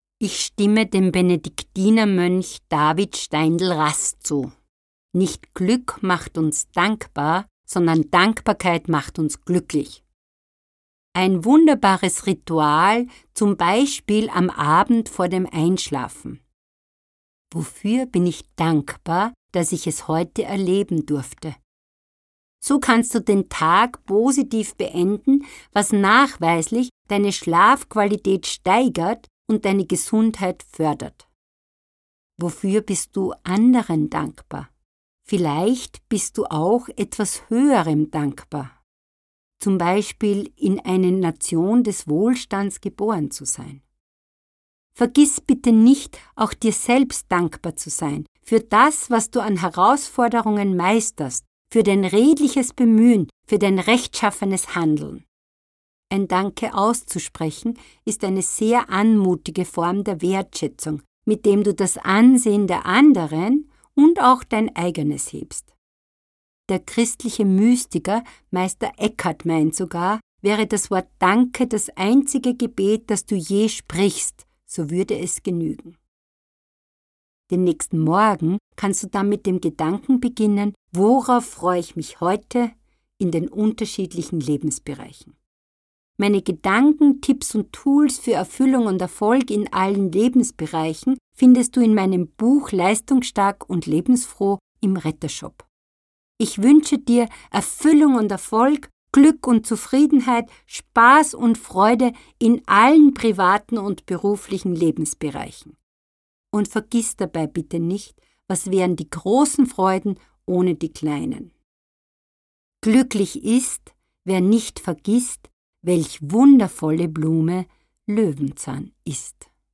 Dieser Audio-Denkanstoß lädt Sie ein, sich bewusst zu machen, wie viele Gründe es gibt, dankbar zu sein.